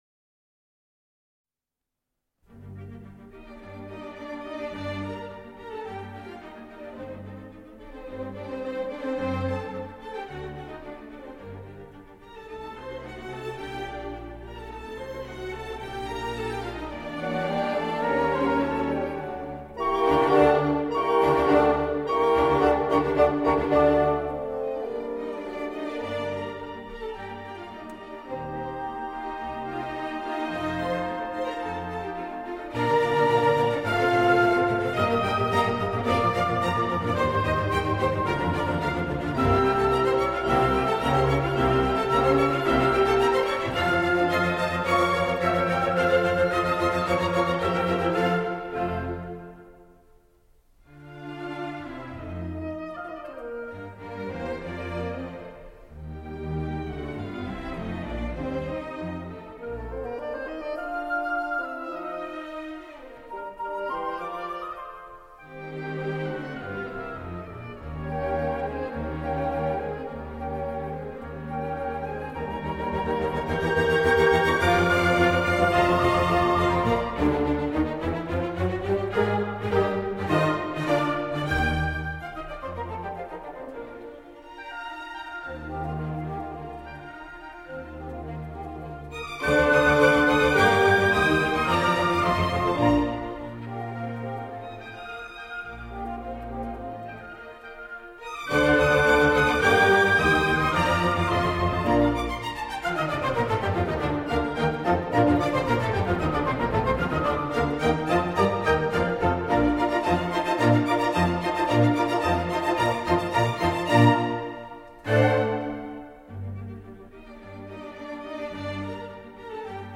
Symphony
~1750 (Classical, Romantic)
Group: Orchestra